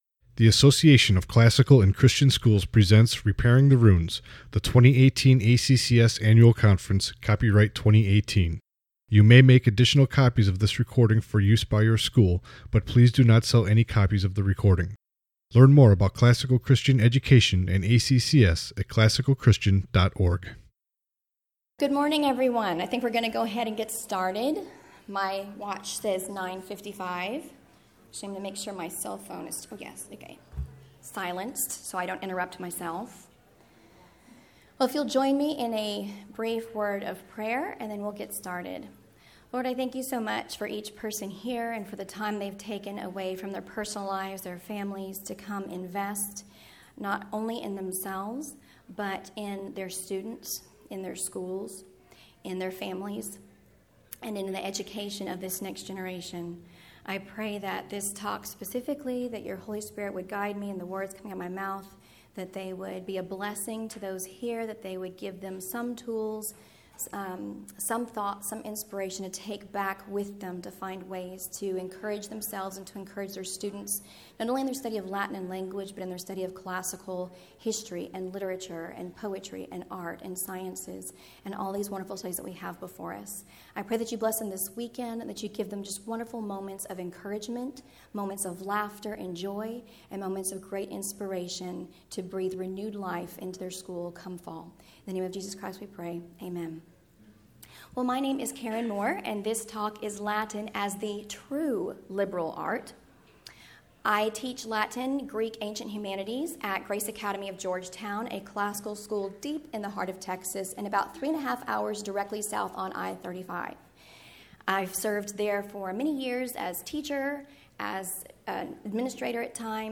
2018 Workshop Talk | 59:26 | All Grade Levels, Latin, Greek & Language